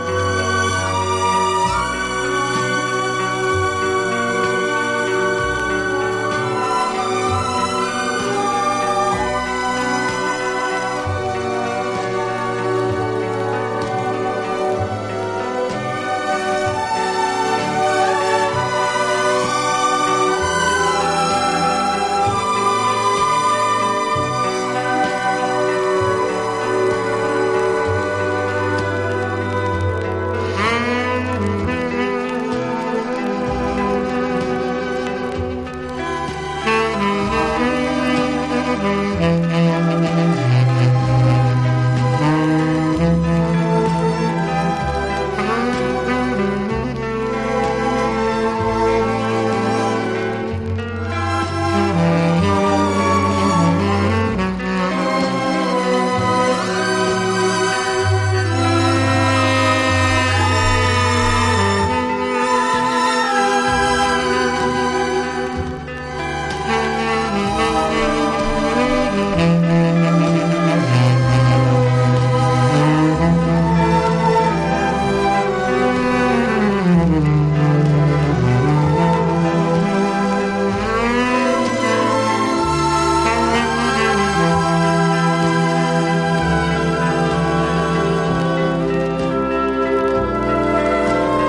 とてつもなく優美でロマンティックな魅惑のストリングス・ラウンジ！